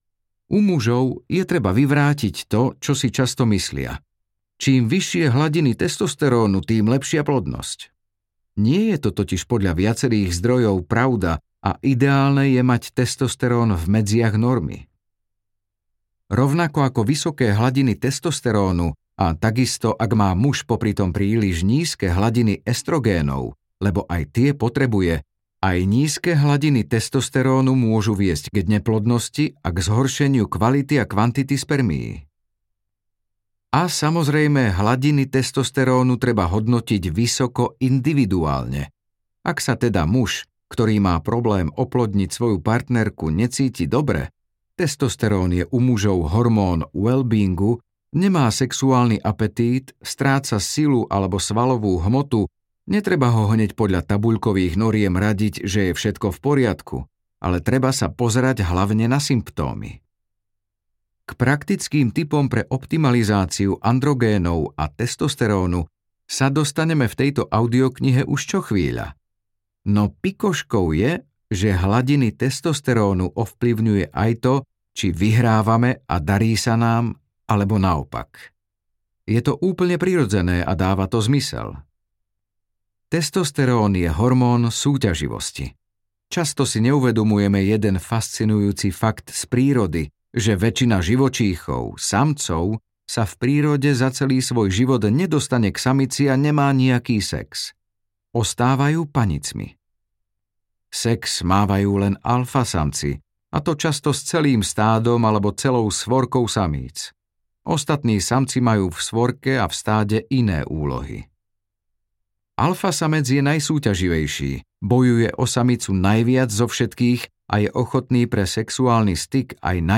Poslechnout delší ukázku (18 min)
audiokniha